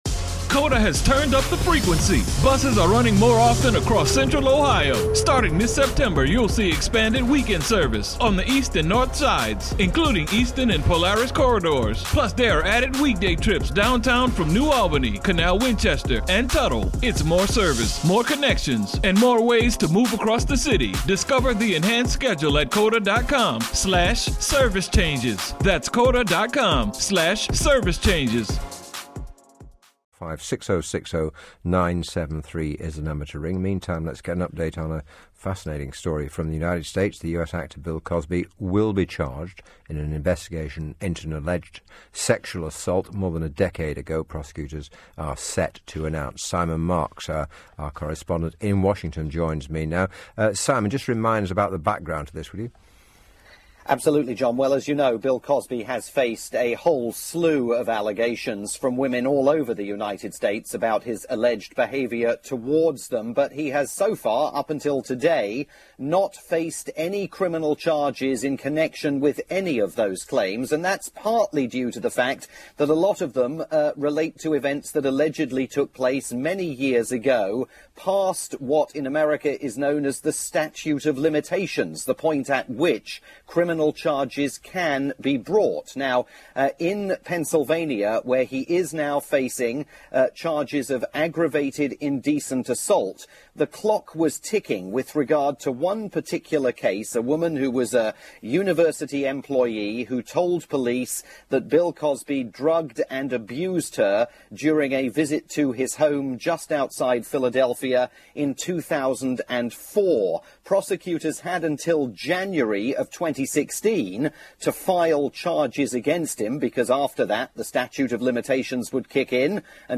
reported the story live on Britain's LBC, speaking here with host John Stapleton.